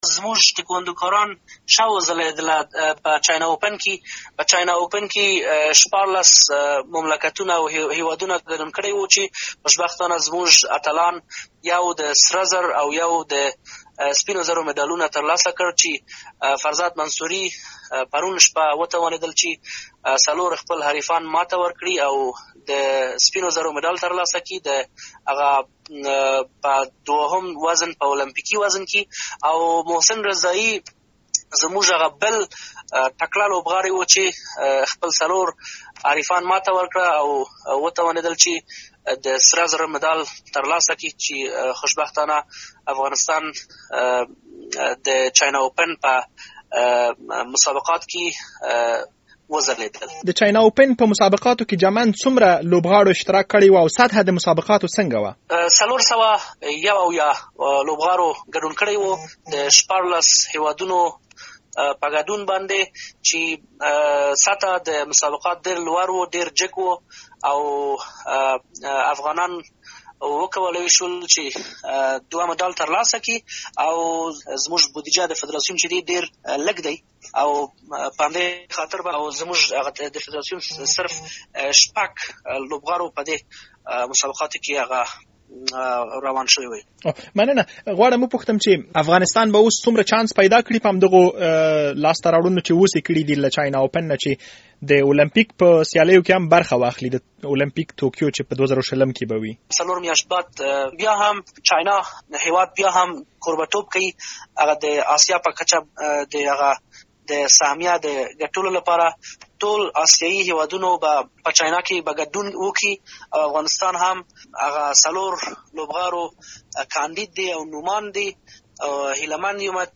ورزشي مرکې